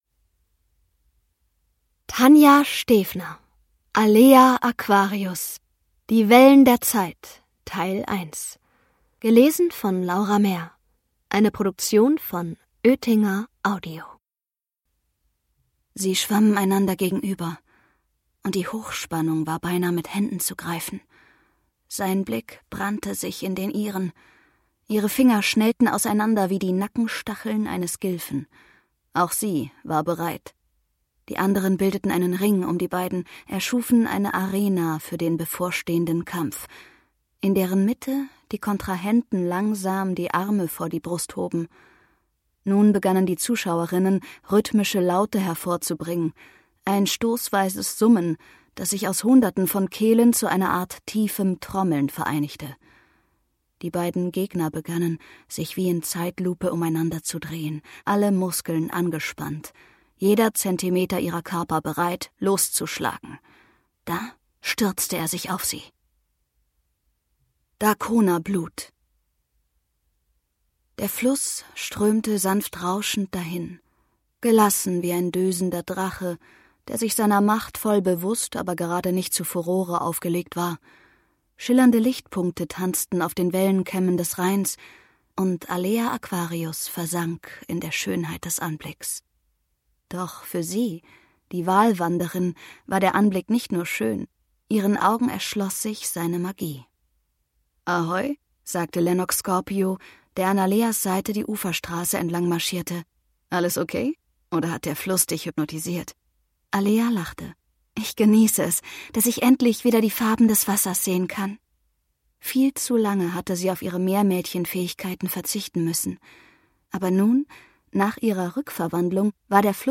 Hörbuch: Alea Aquarius 8 Teil 1.